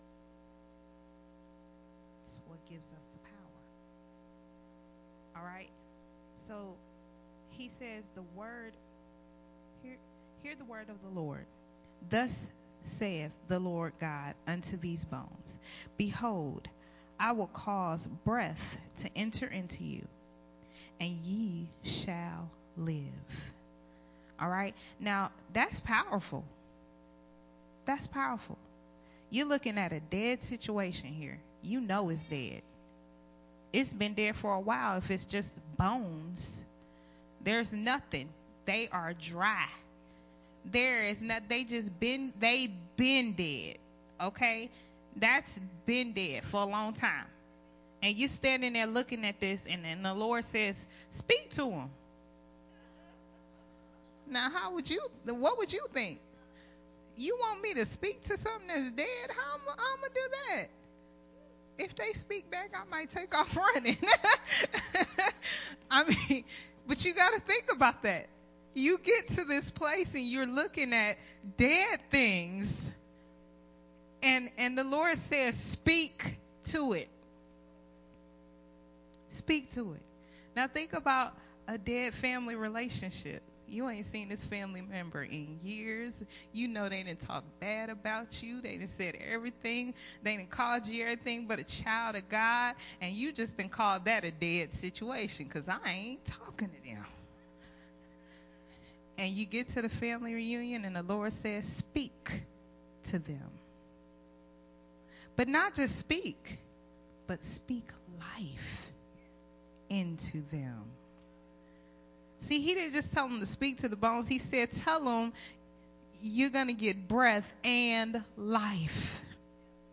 Sunday Morning Refreshing, recorded at Unity Worship Center on 8/1/2021.